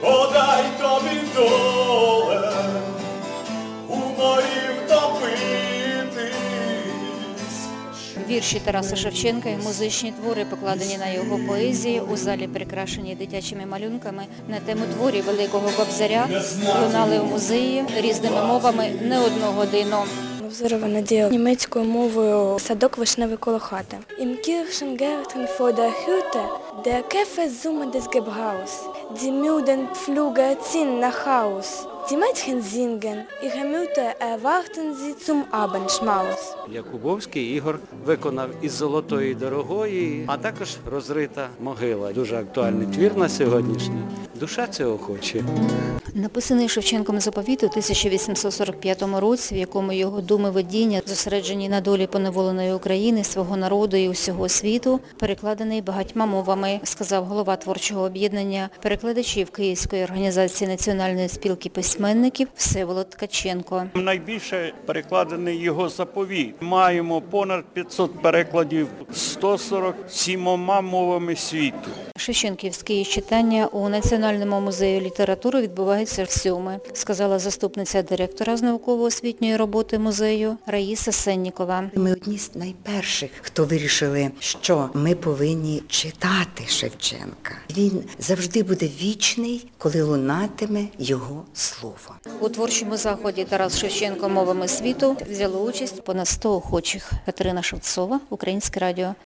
У Національному музеї літератури читали твори Кобзаря (аудіо)
Тут його вірші декламували  відомі письменники, актори, художники, науковці, учні, студенти англійською, німецькою, французькою, болгарською, польською, малайзійською, хінді, іншими мовами світу.